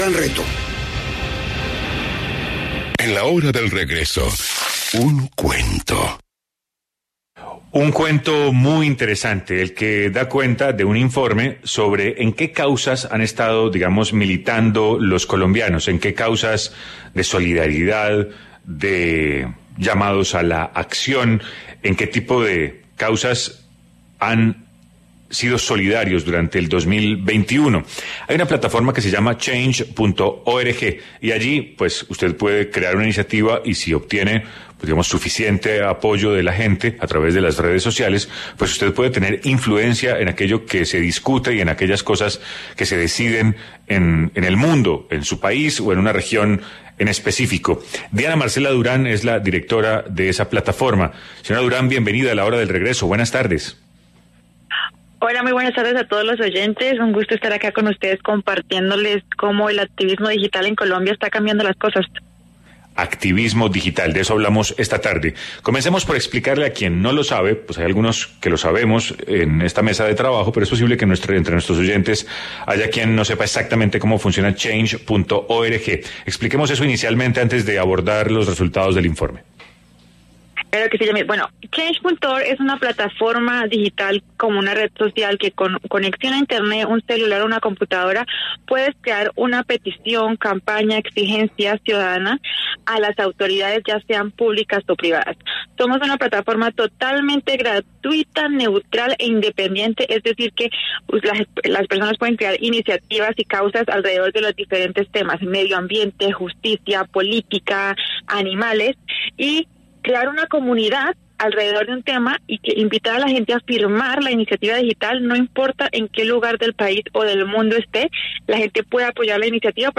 En diálogo con La Hora del Regreso